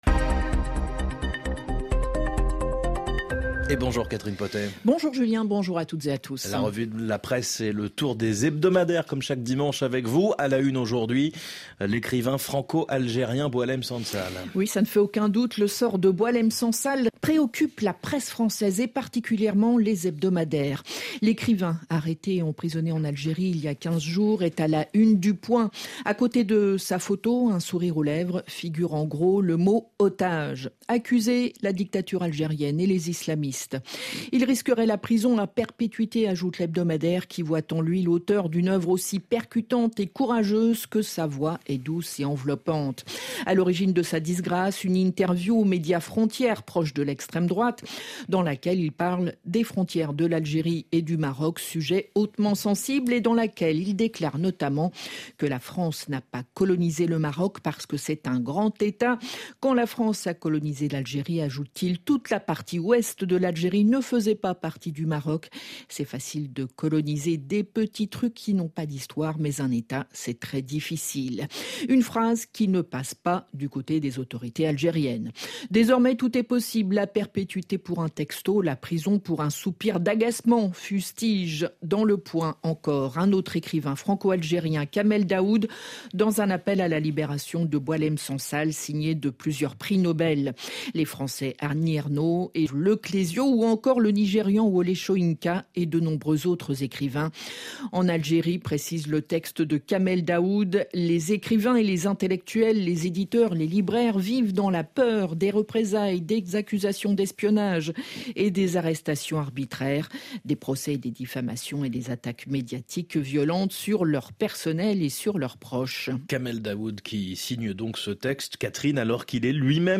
Revue de presse des hebdomadaires français